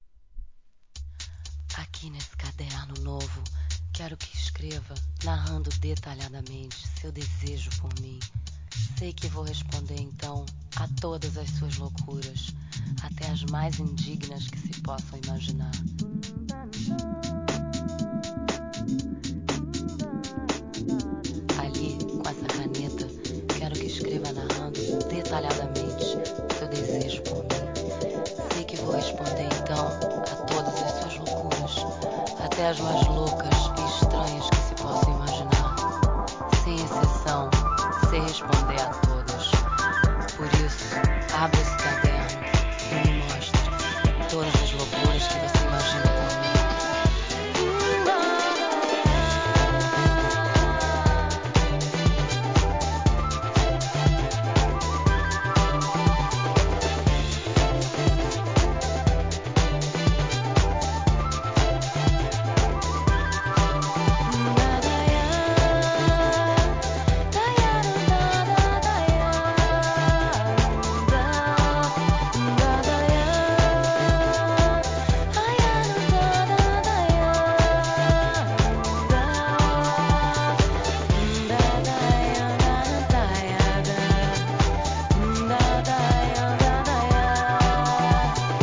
クロスオーバー、ブレイクビーツ、HOUSE